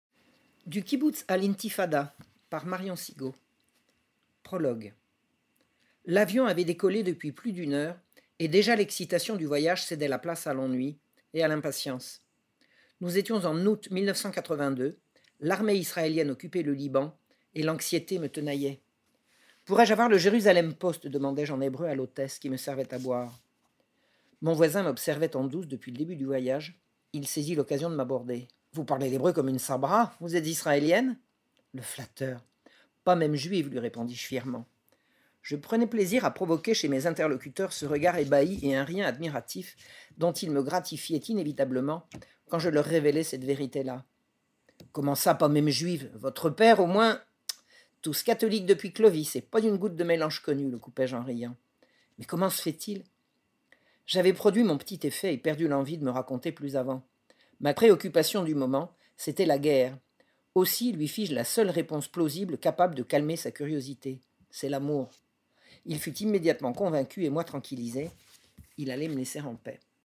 Du Kibboutz à l’Intifada – Livre audio